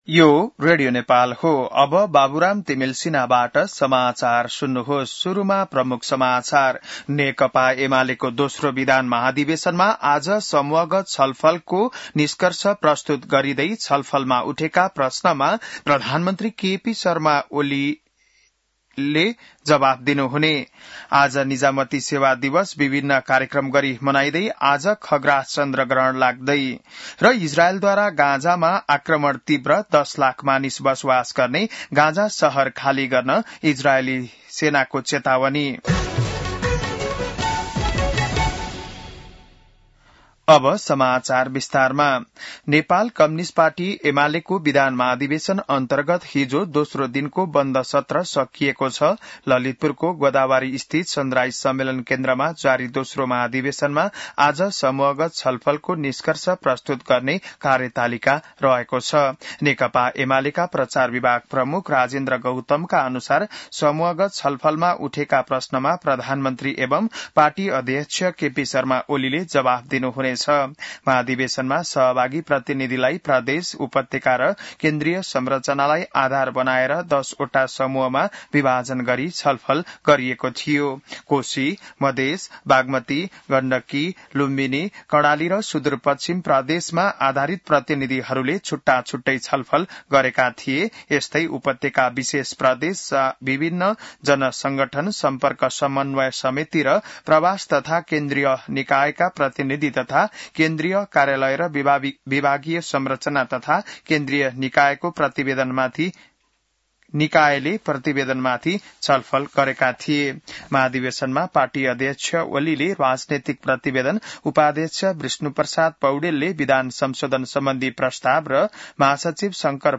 बिहान ९ बजेको नेपाली समाचार : २२ भदौ , २०८२